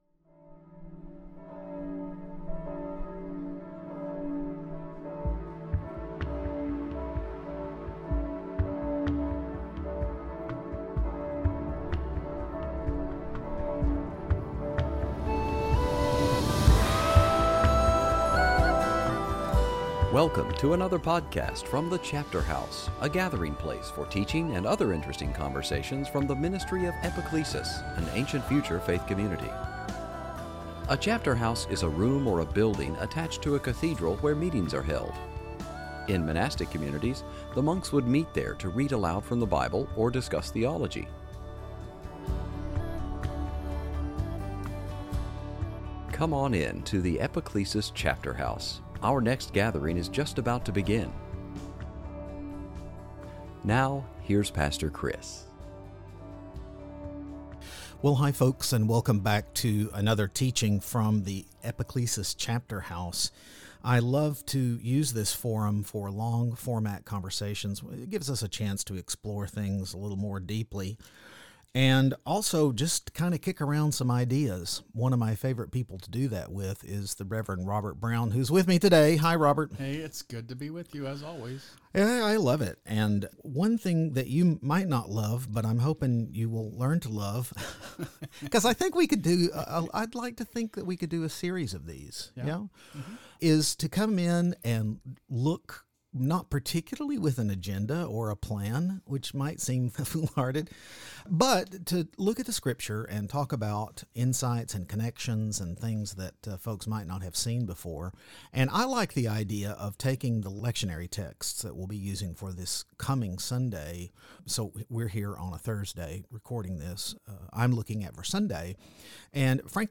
It was an interesting conversation.